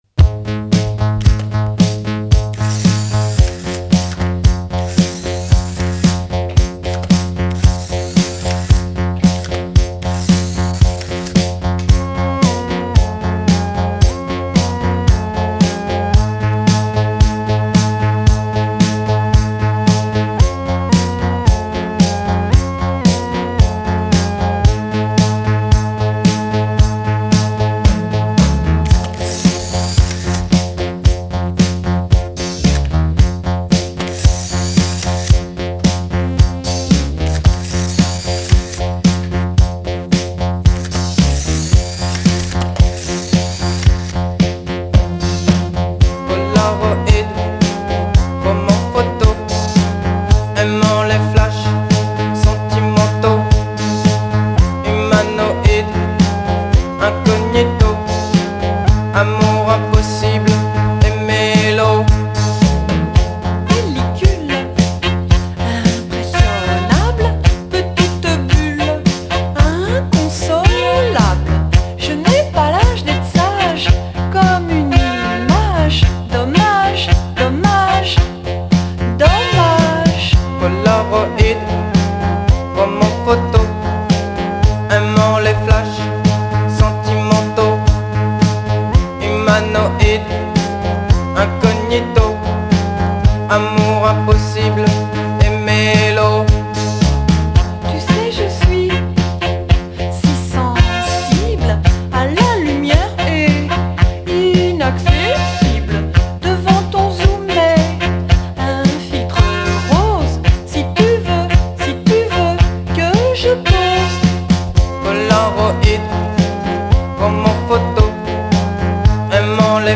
Filed under coldwave, paris